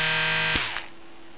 SF -- Power Failure
pwrfail.aif